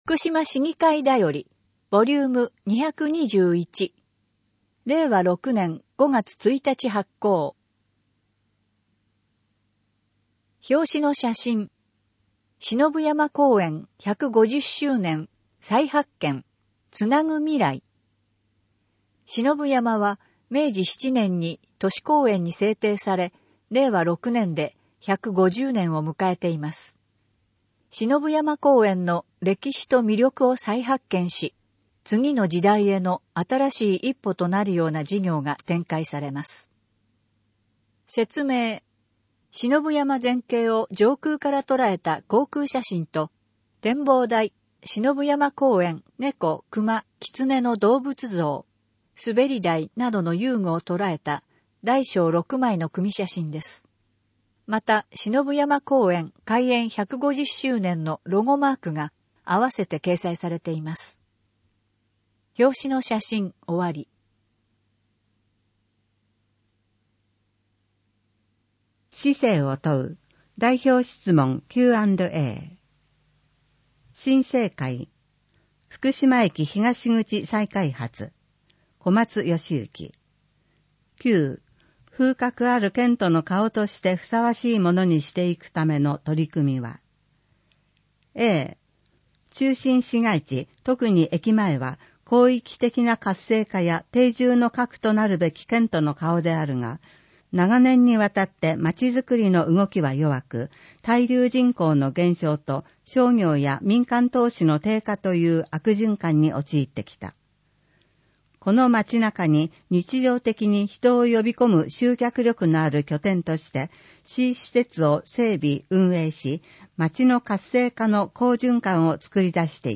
福島市議会では、「ふくしま市議会だより」の内容を音声で提供する「声の市議会だより（音声案内）」を配信しています。